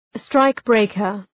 Προφορά
{‘straık,breıkər}